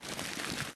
crumple2.ogg